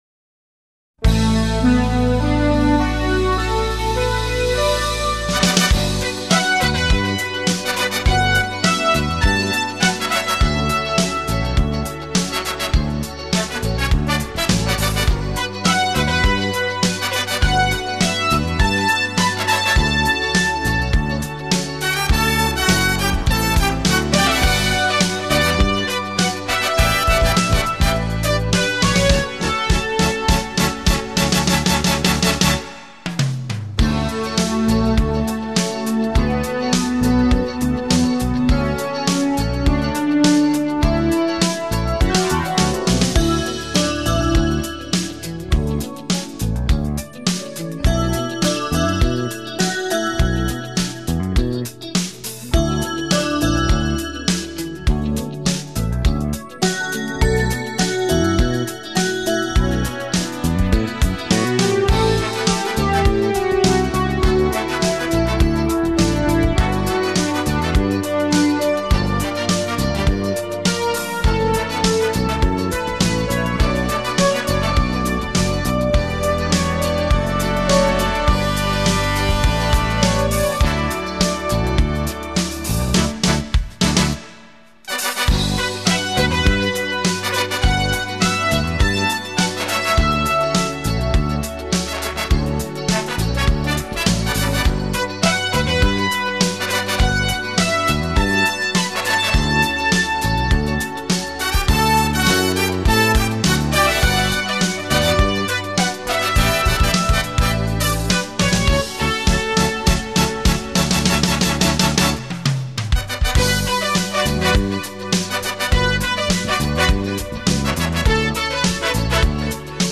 음악도 따듯한 감성이 살아있어 무척 좋네요.